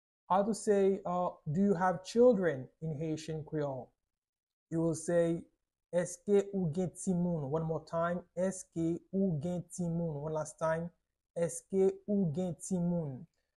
“Èske ou gen timoun?” Pronunciation in Haitian Creole by a native Haitian can be heard in the audio here or in the video below:
How-to-say-Do-you-have-children-in-Haitian-Creole-Eske-ou-gen-timoun-pronunciation-by-a-native-Haitian-Cr.mp3